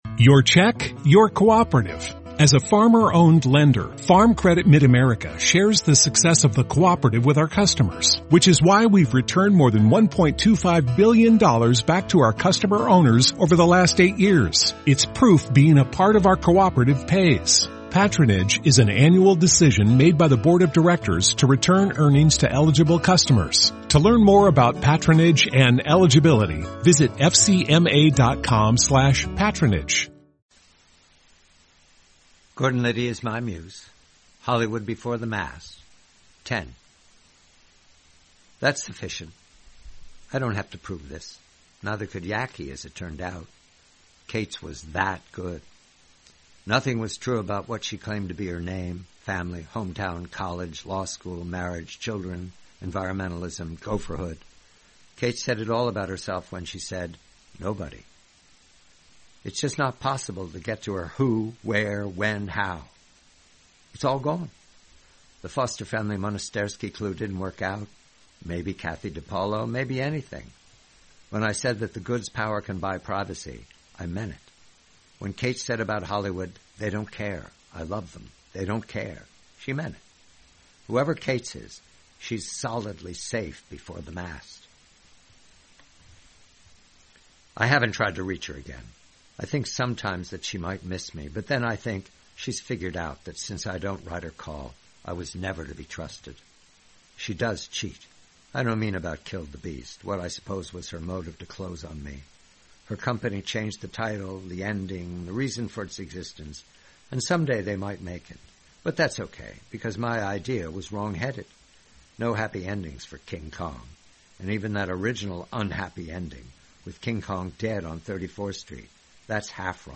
Read by the host.